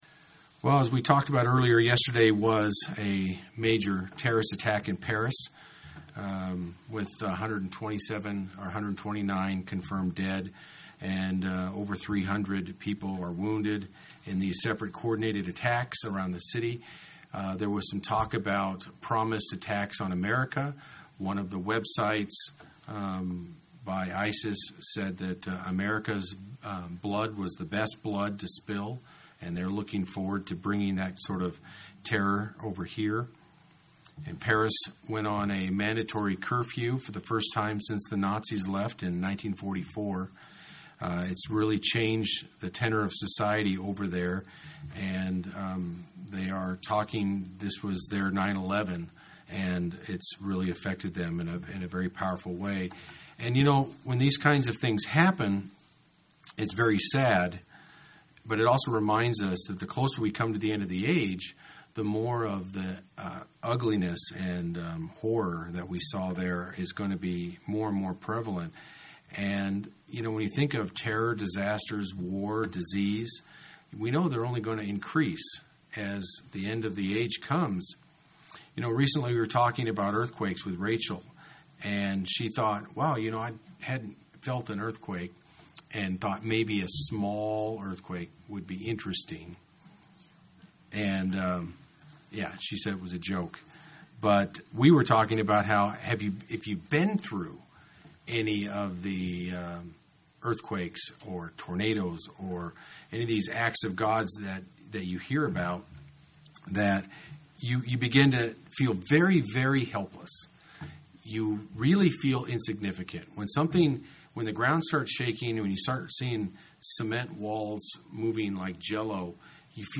UCG Sermon Notes Yesterday there was a major Terrorist Attack in Paris France.